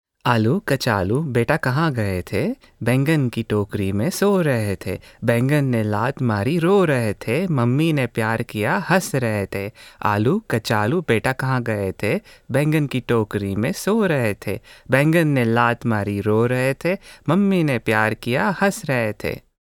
Nursery Rhymes